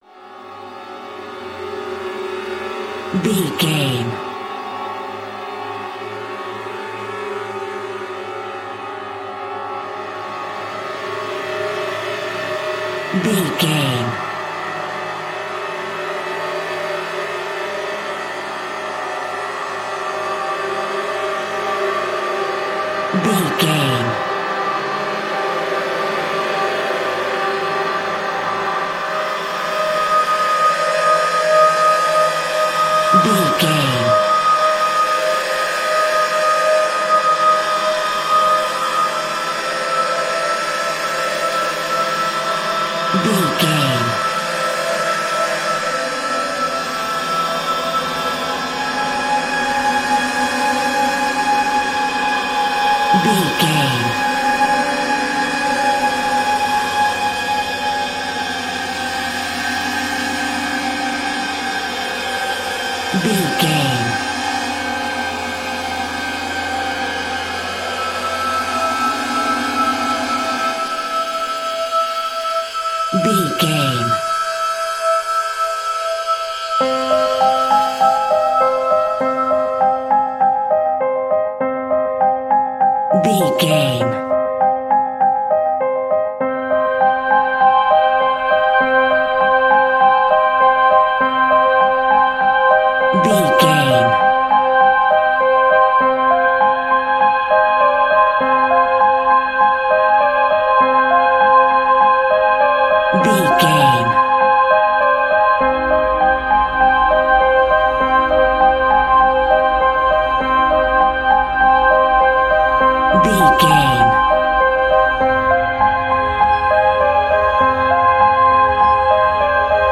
Scary Horror Star.
Atonal
scary
tension
ominous
dark
suspense
haunting
eerie
synthesizer
creepy
horror music
Horror Pads
Horror Synths